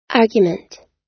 Транскрипция и произношение слова "argument" в британском и американском вариантах.
argument__us_1.mp3